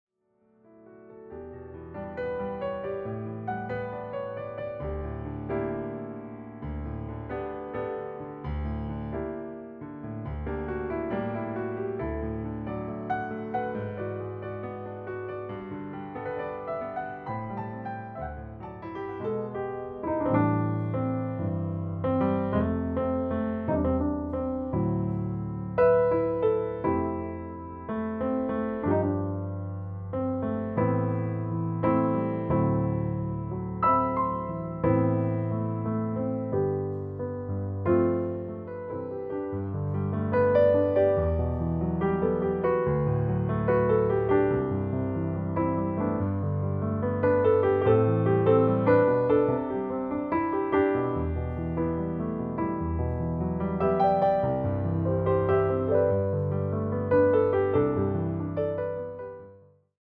reimagined as solo piano pieces